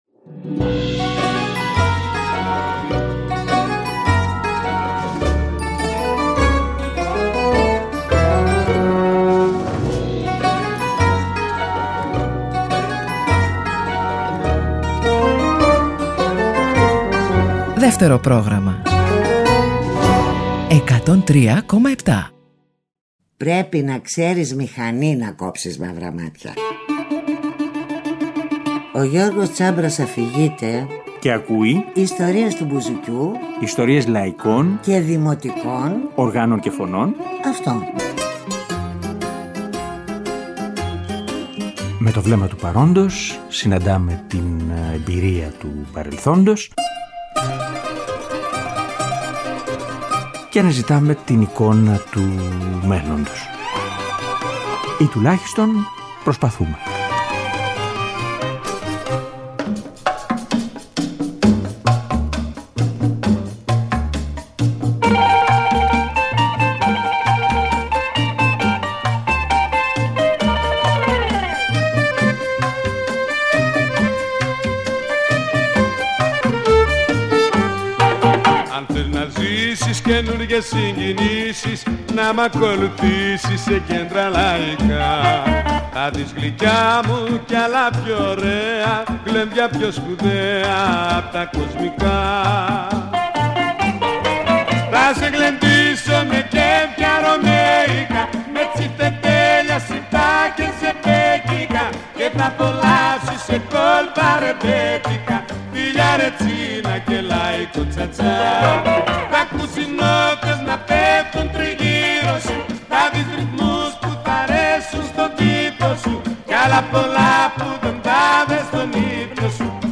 Από τα χρόνια του τζουκ μποξ και των μικρών δίσκων, λαϊκά τραγούδια – βιώματα, τραγούδια – παραγγελίες, τραγούδια για το γλέντι αλλά και για τη μελαγχολία της βραδιάς.